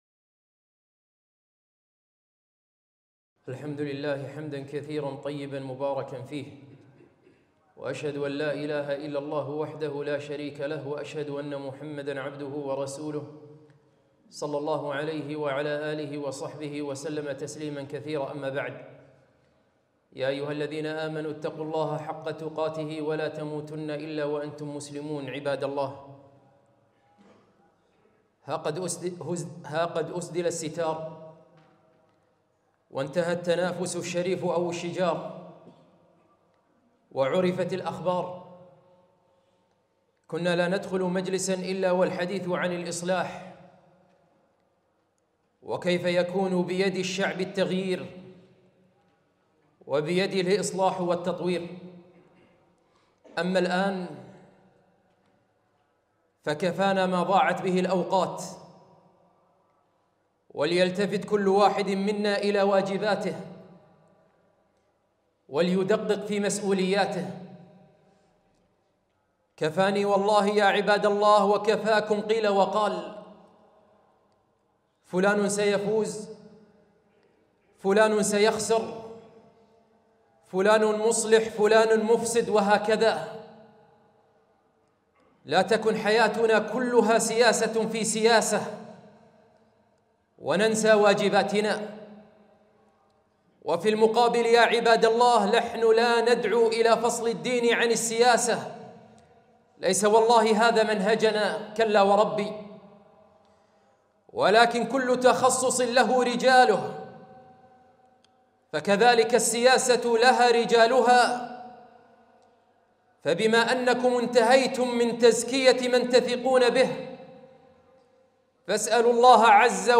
خطبة - ما بعد الانتخابات